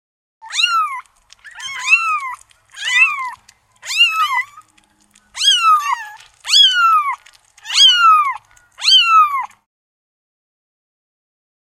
Брошенные котята в коробке жалобно мяукают